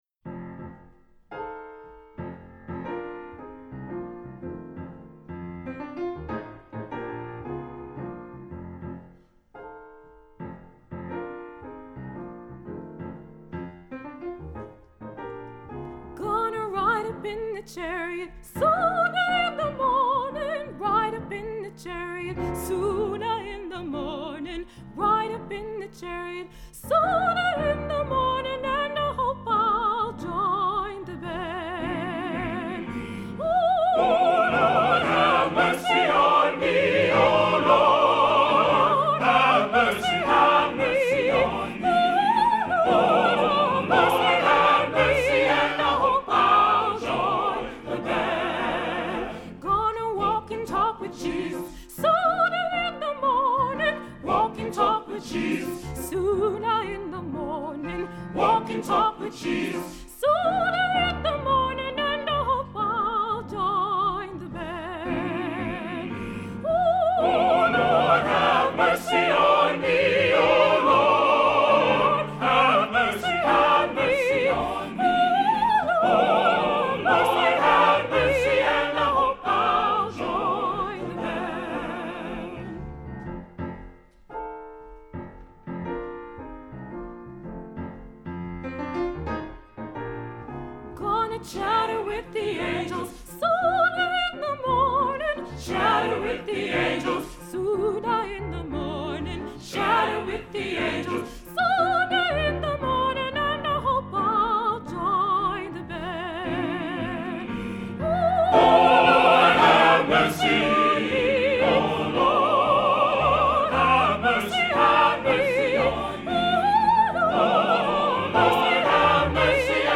Voicing: SATB; Soprano Solo